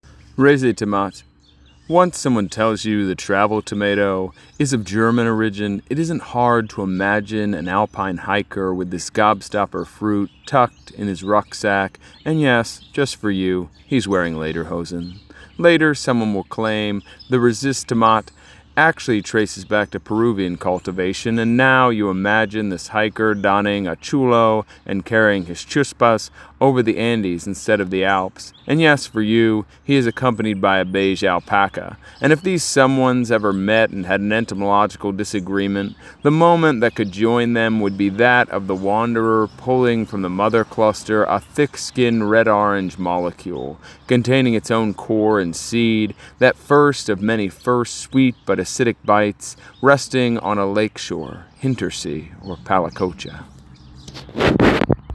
Or you can let them read to you!